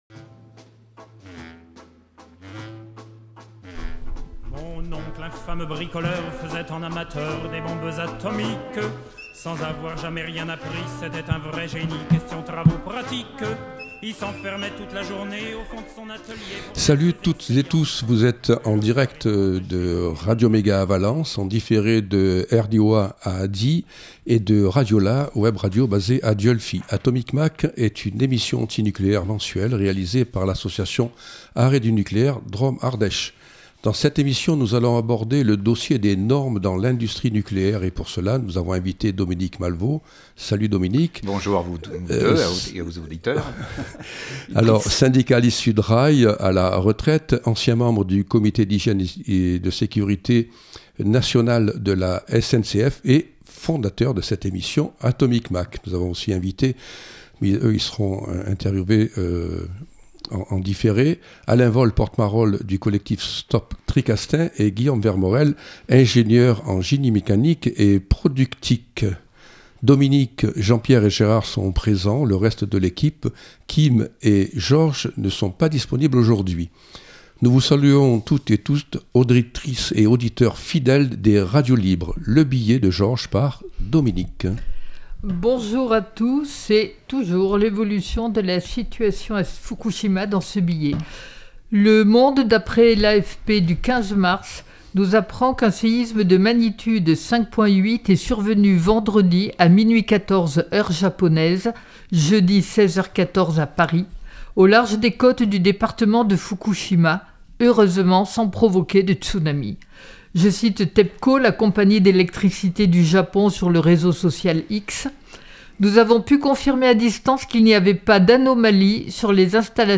Atomic Mac, c’est l’émission radio mensuelle de l’association Arrêt du nucléaire Drôme-Ardèche.